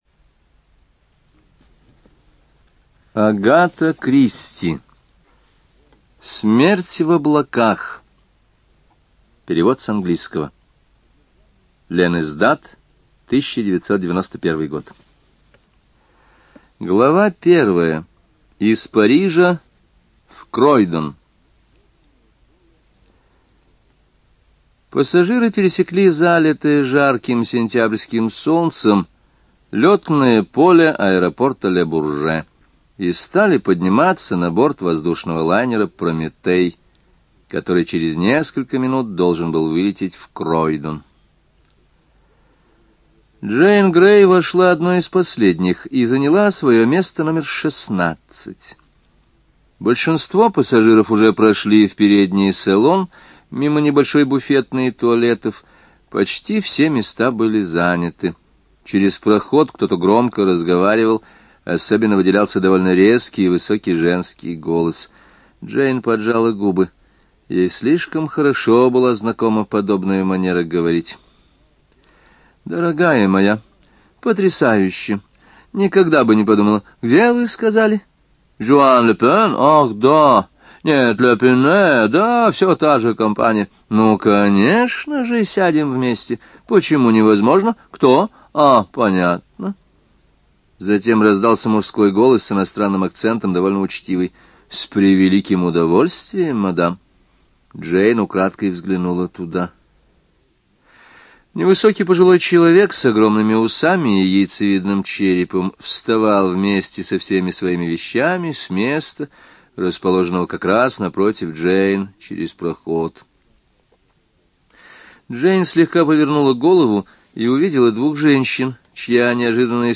ЖанрДетективы и триллеры, Классическая проза
Студия звукозаписиЛогосвос